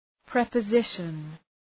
Προφορά
{,prepə’zıʃən}
preposition.mp3